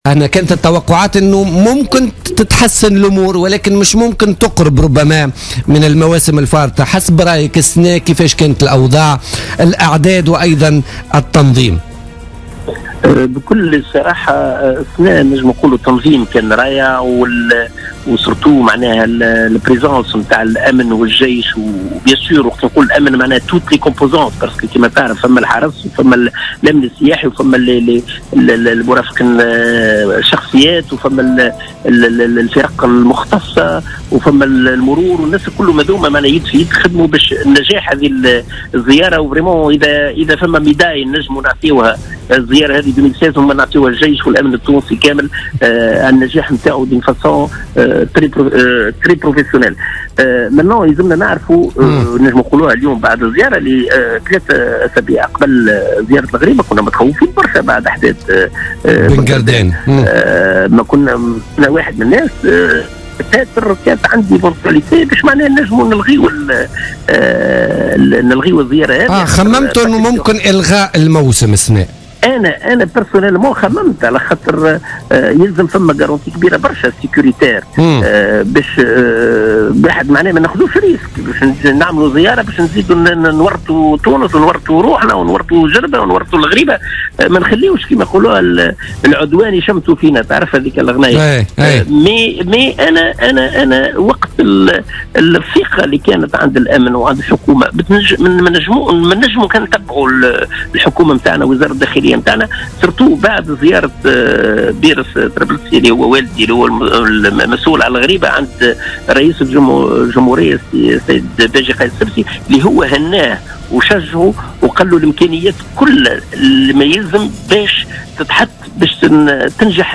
أكد منظم رحلات زيارة الغريبة روني الطرابلسي في مداخلة له في بوليتيكا اليوم الجمعة 27 ماي أن تنظيم زيارة الغريبة هذا العام كان تنظيما رائعا بسبب الحضور الأمني والعسكري الجيدين والتعزيزات الكبيرة التي أمنت هذه التظاهرة.